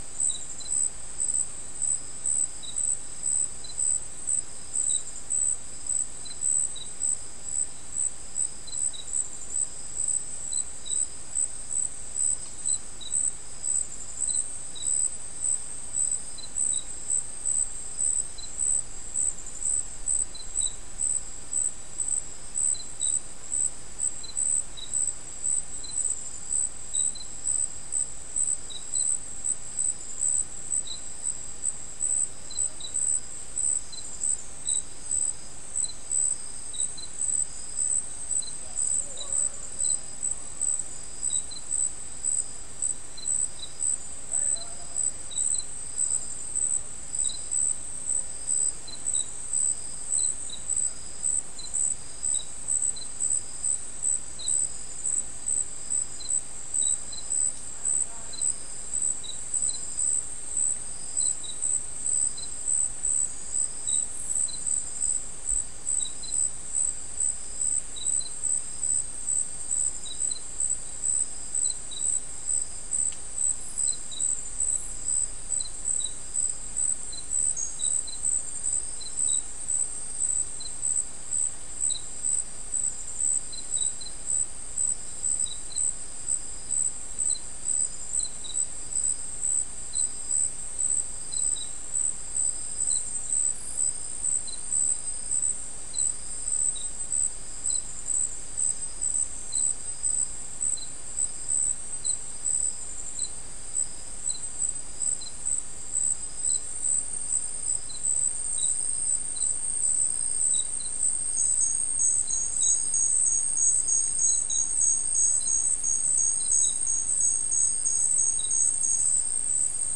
Soundscape
South America: Guyana: Mill Site: 3
Recorder: SM3